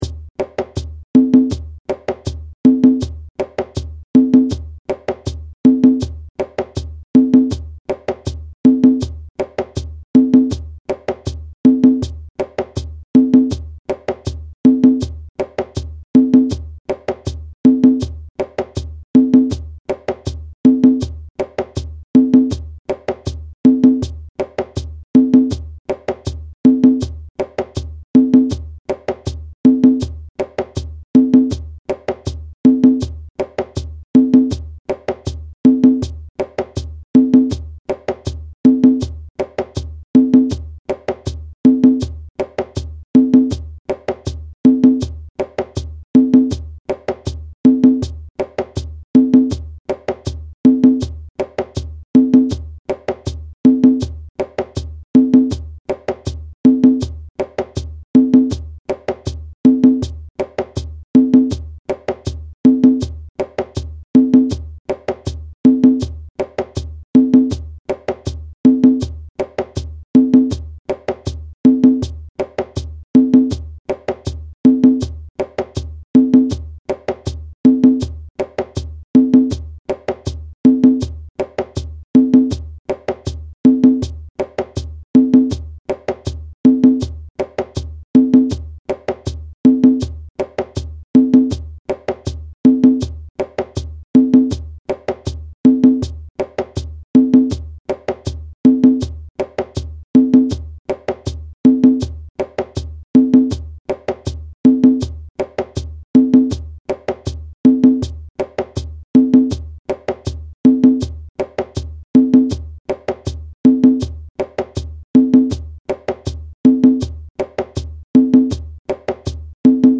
WEST AFRICAN SAMBA – This playful, informal dance rhythm combines West African and Afro-Brazilian rhythmic concepts.
middle part audio (with shekeré)